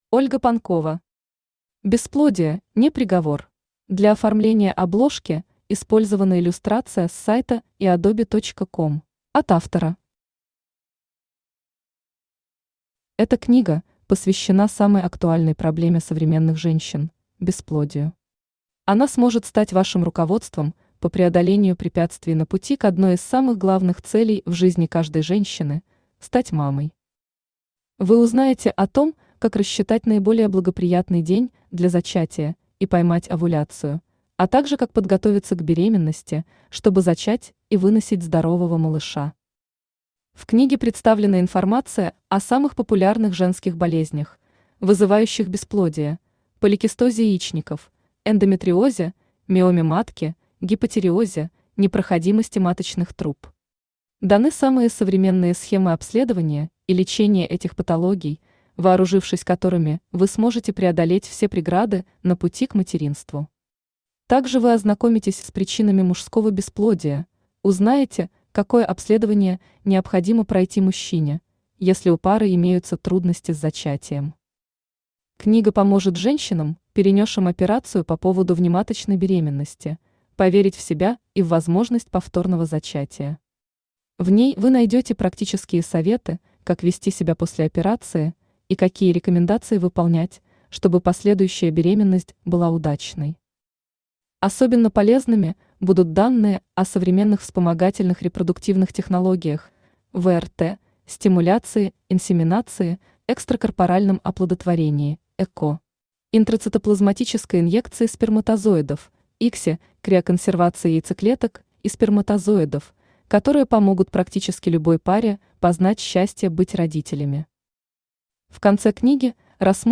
Аудиокнига Бесплодие – не приговор!
Автор Ольга Юрьевна Панкова Читает аудиокнигу Авточтец ЛитРес.